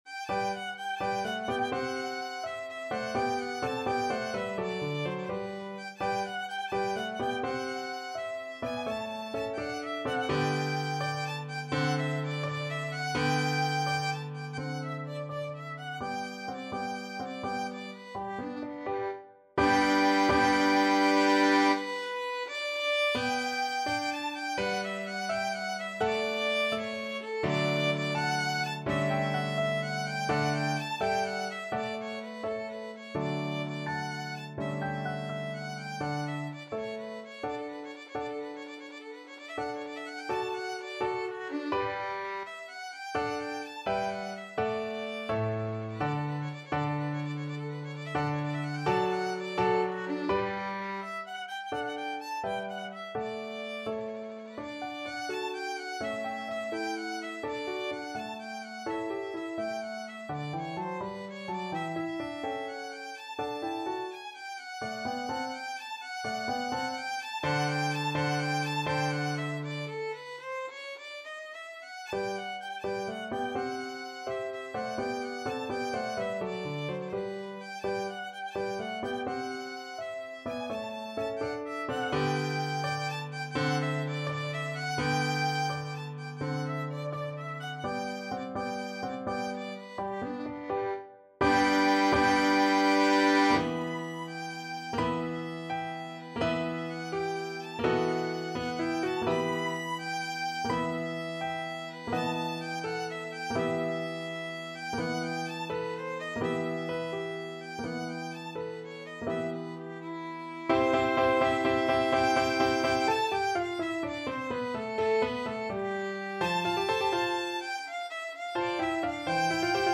Violin
G major (Sounding Pitch) (View more G major Music for Violin )
Grazioso .=84
6/8 (View more 6/8 Music)
Classical (View more Classical Violin Music)